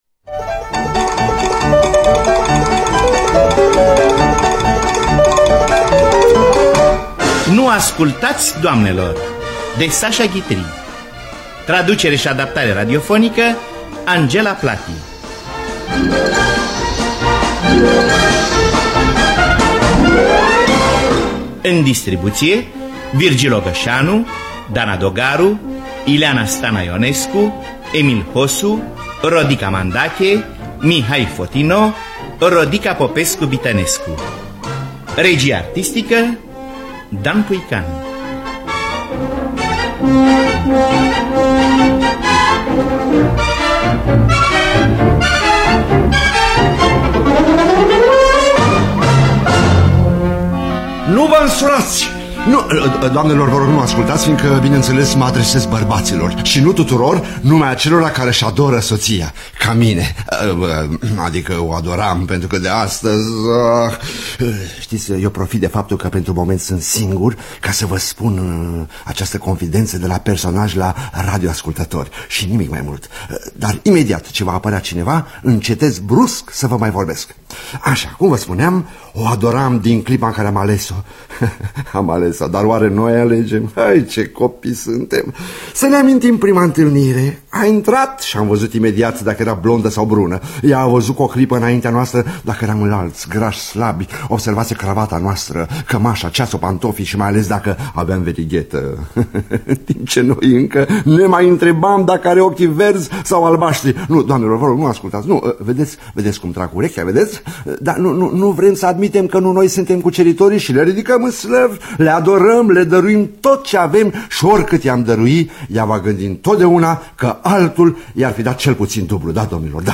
Nu ascultaţi, doamnelor! de Sacha Guitry – Teatru Radiofonic Online